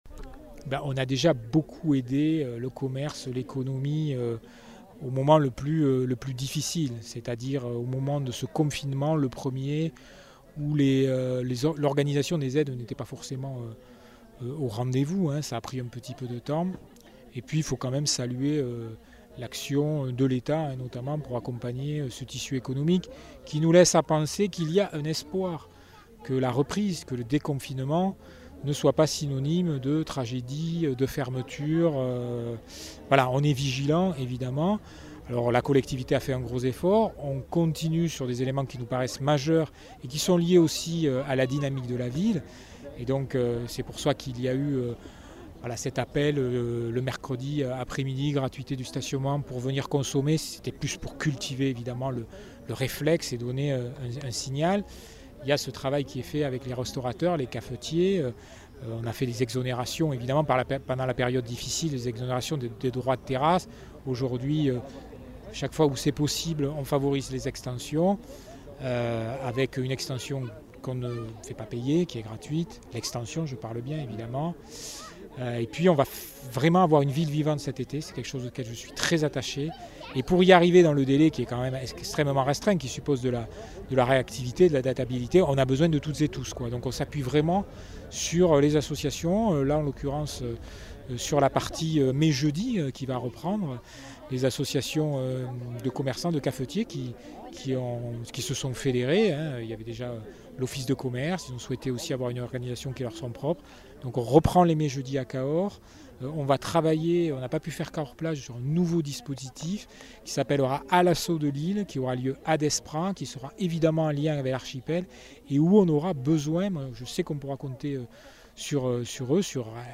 Interviews
Invité(s) : Jean Marc Vayssouze Faure, maire de Cahors